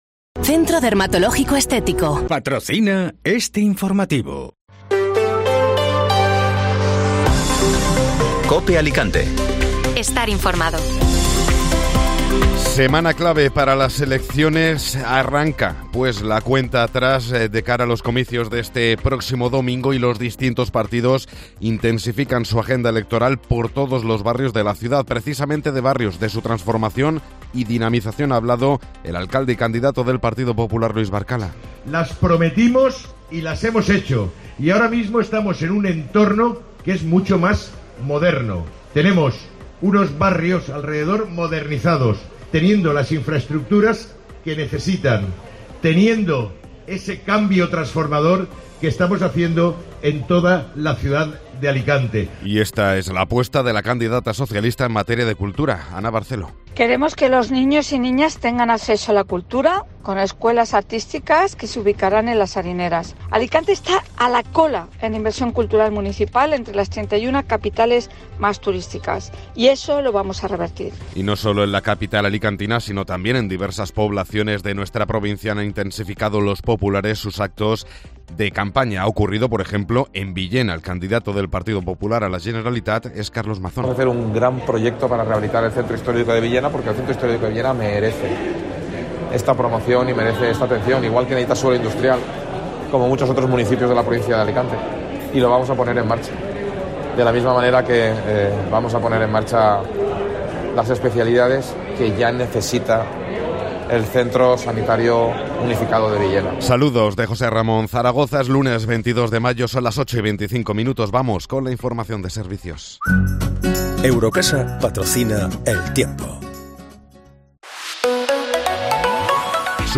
Informativo Matinal (Lunes 22 de Mayo)